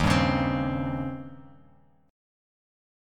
D#13 Chord
Listen to D#13 strummed